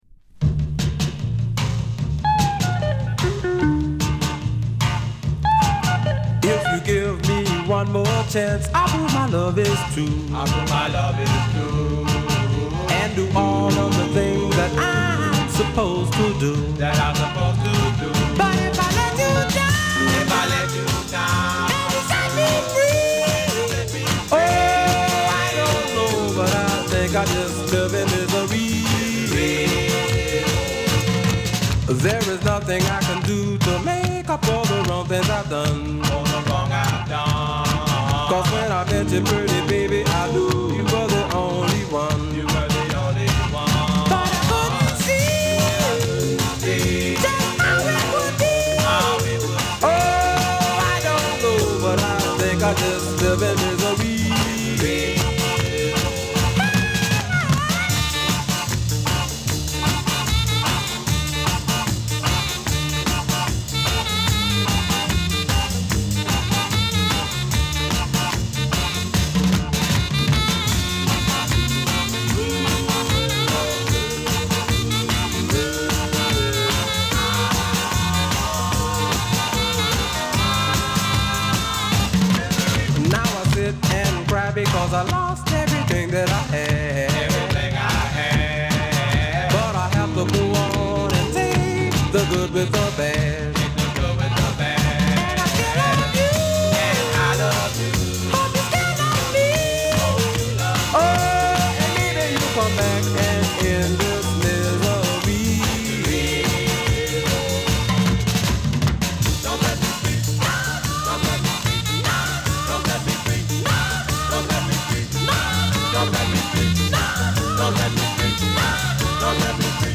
ファルセット・ヴォーカルとコーラスが対比が美しいマイナー調の切ないR&Bダンス・サウンド。